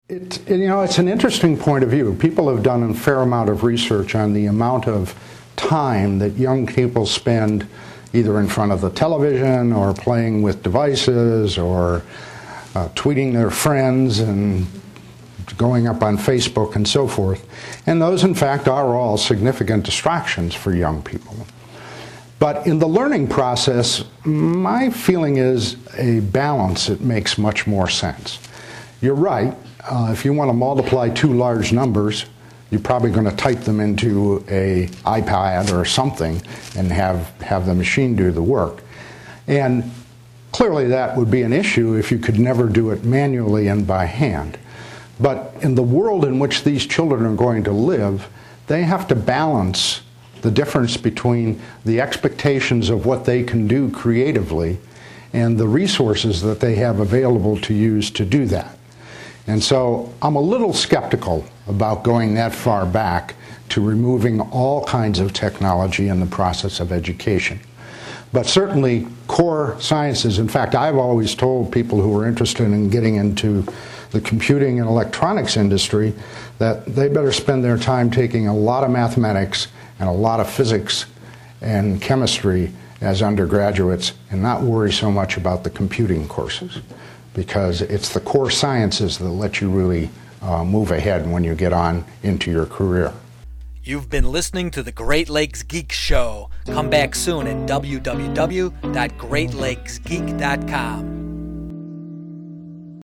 Speaking at the City Club of Cleveland, Chuck Geschke gave insights into the birth of Silicon Valley and the computer industry.
Here are some audio snippets of Geschke's answers to audience questions.